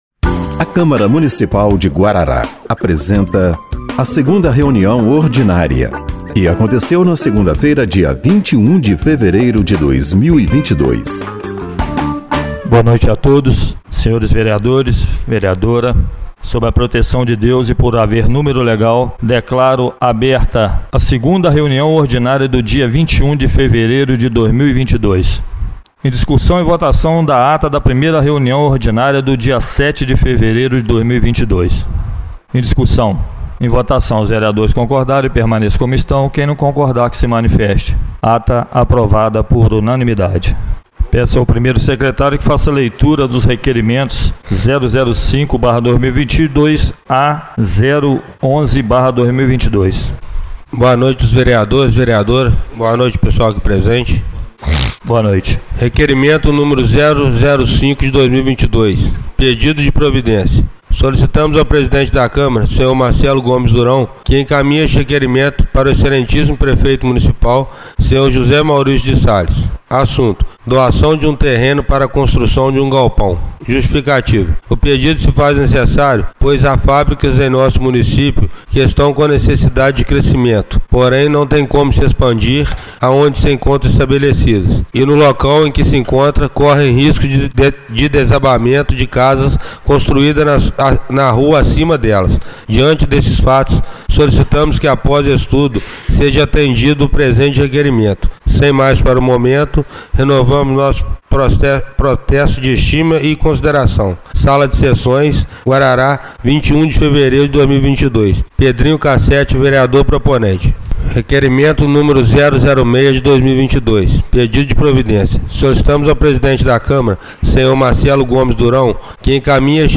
2ª Reunião Ordinária de 21/02/2022